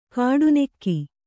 ♪ kāḍu nekki